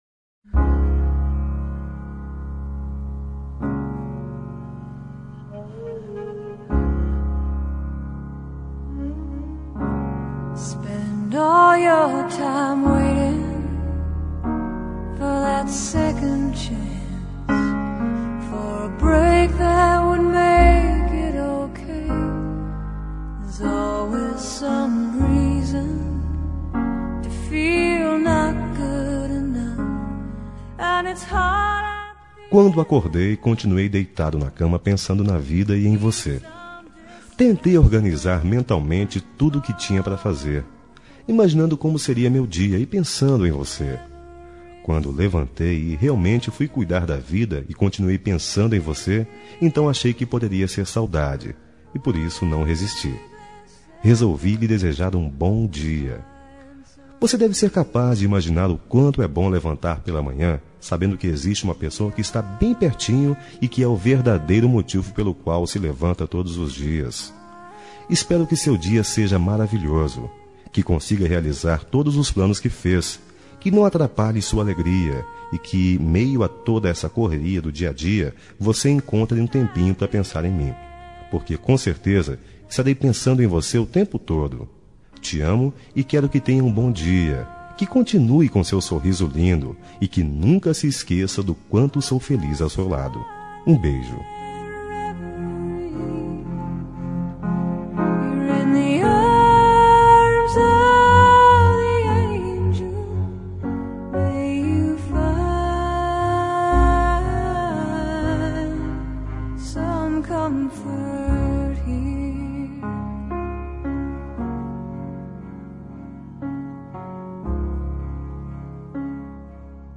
Telemensagem de Bom dia – Voz Masculina – Cód: 6324 – Romântica